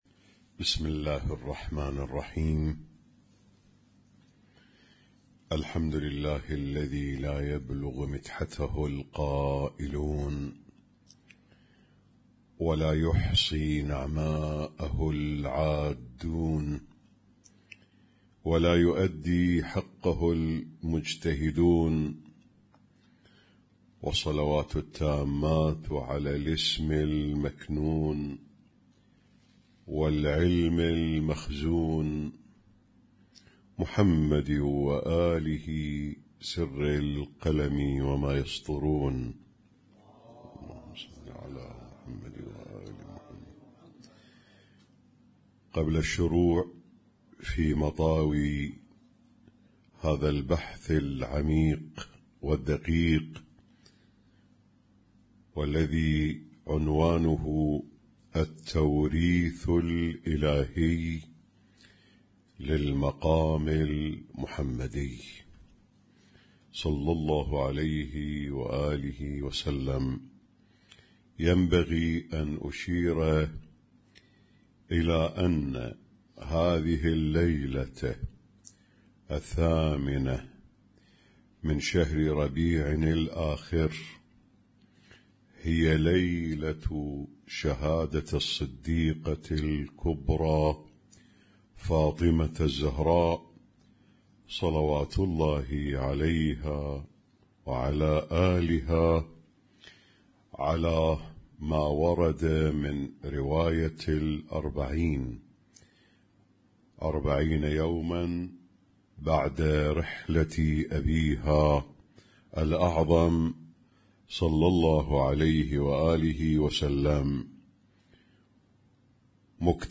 اسم التصنيف: المـكتبة الصــوتيه >> الدروس الصوتية >> الرؤية المعرفية الهادفة